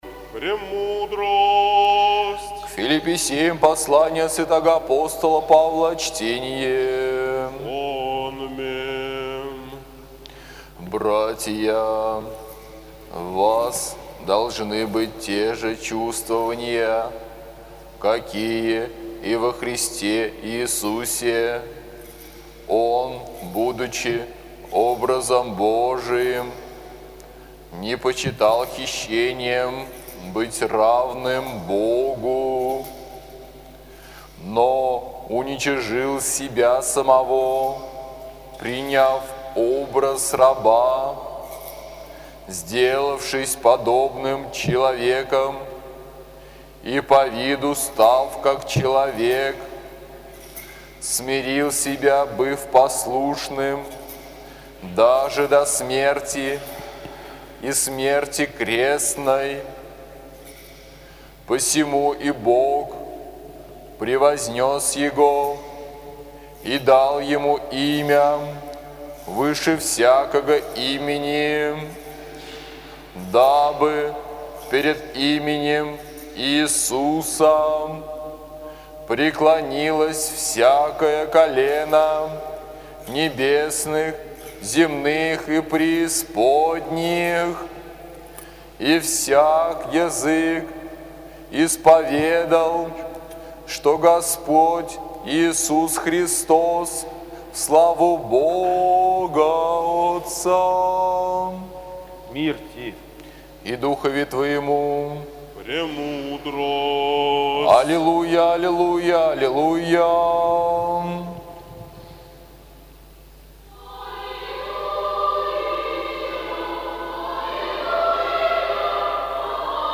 АПОСТОЛЬСКОЕ ЧТЕНИЕ НА ЛИТУРГИИ